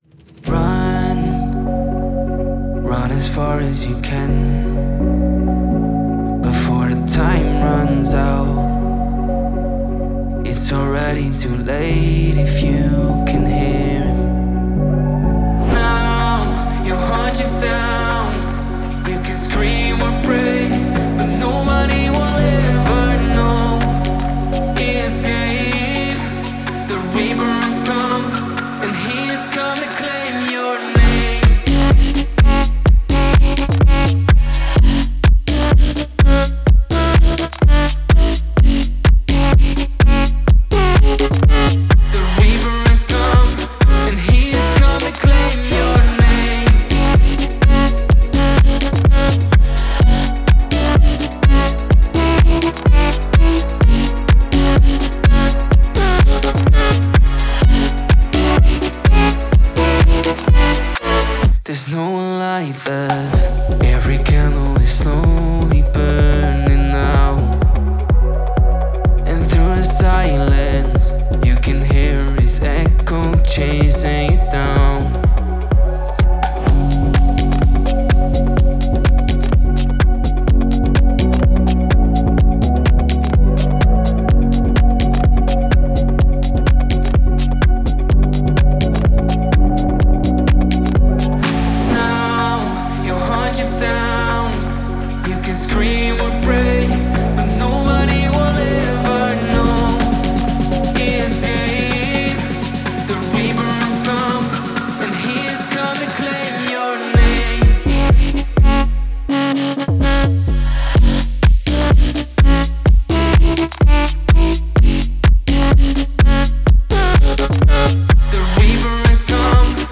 126 Bpm – F#minor